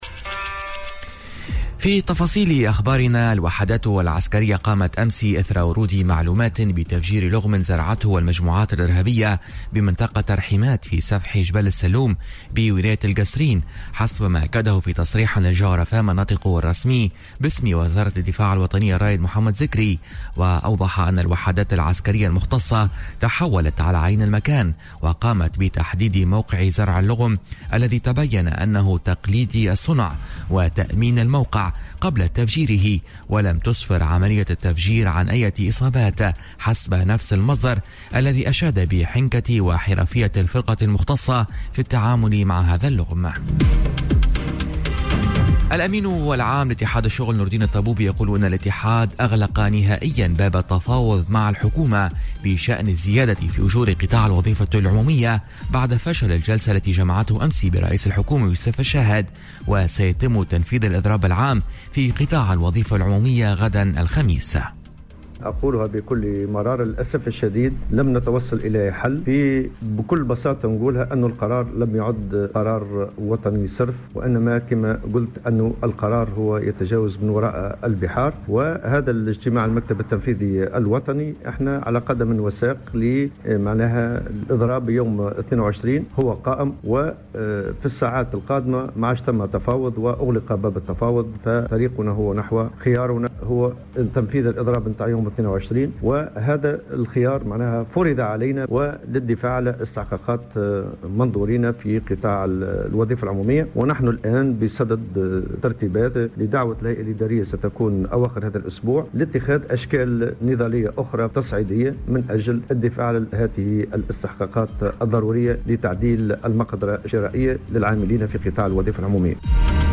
نشرة أخبار السابعة صباحا ليوم الإربعاء 21 نوفمبر 2018